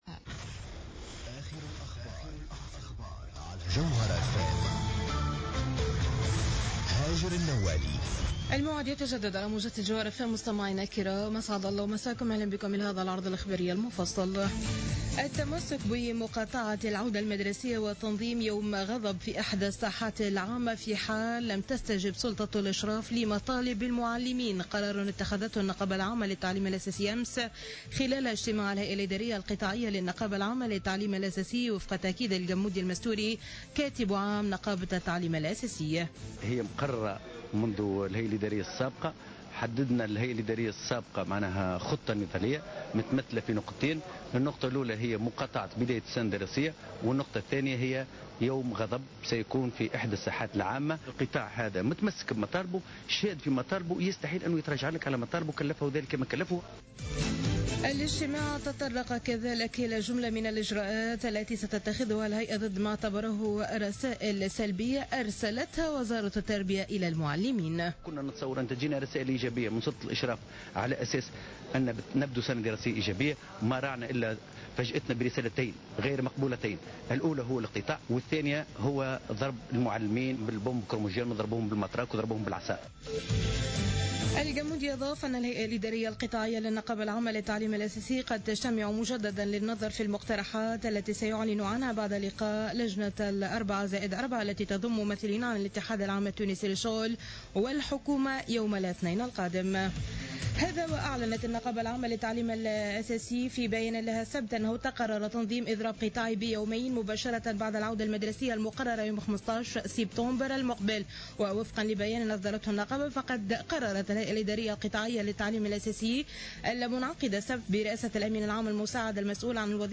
نشرة أخبار منتصف الليل ليوم الأحد 23 أوت 2015